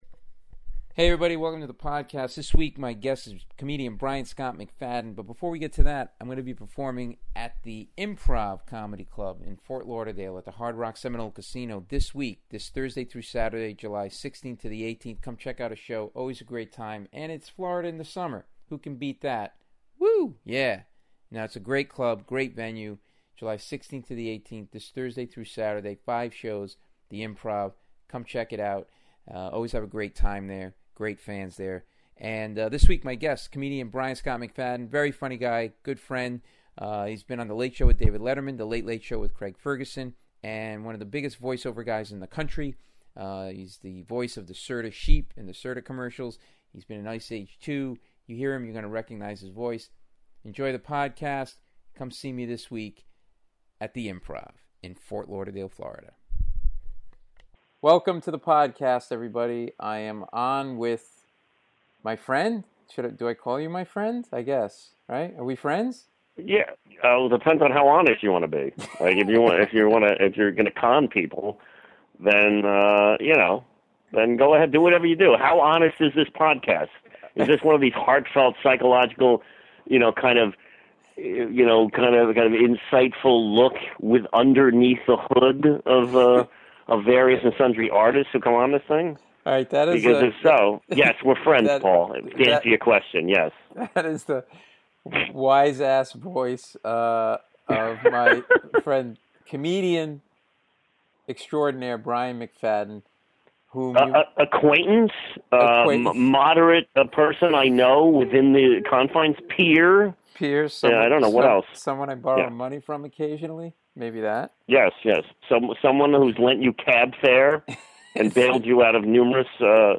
We insult each other a lot and have a great talk about growing up a child star, having a famous father and stage mother and why stand up has been